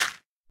minecraft / sounds / dig / gravel1.ogg
gravel1.ogg